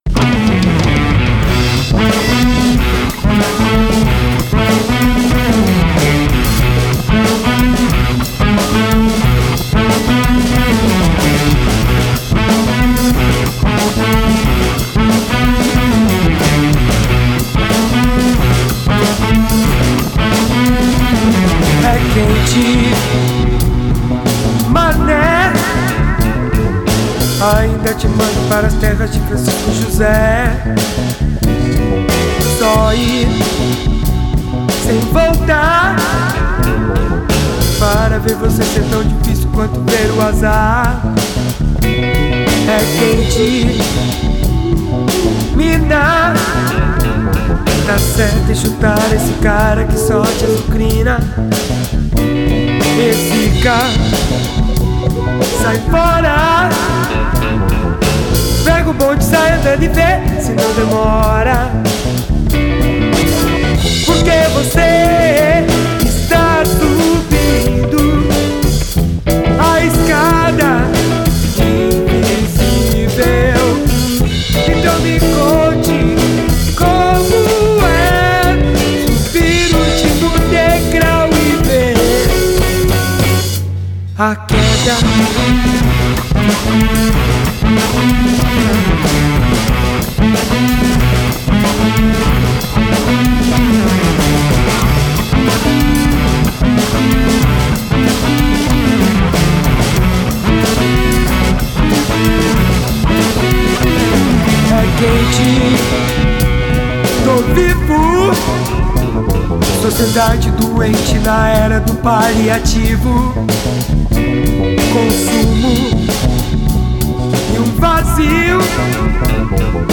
Genre: Alternative.